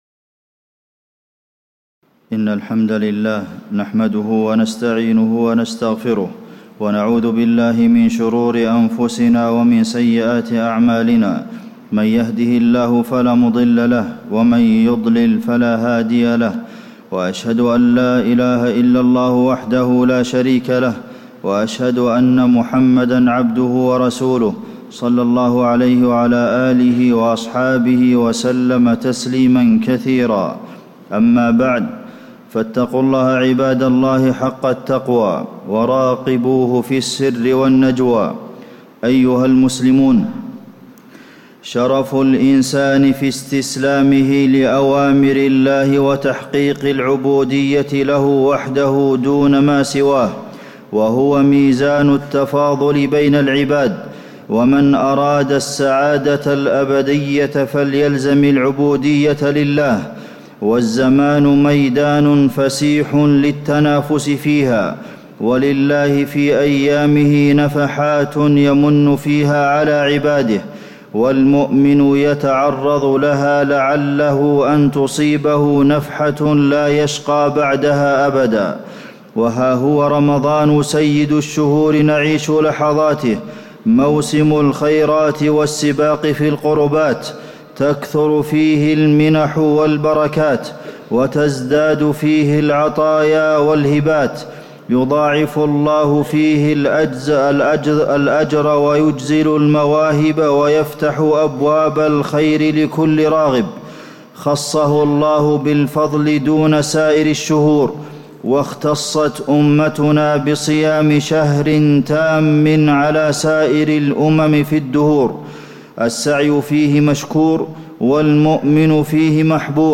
تاريخ النشر ١٢ رمضان ١٤٣٧ هـ المكان: المسجد النبوي الشيخ: فضيلة الشيخ د. عبدالمحسن بن محمد القاسم فضيلة الشيخ د. عبدالمحسن بن محمد القاسم العمل الصالح في رمضان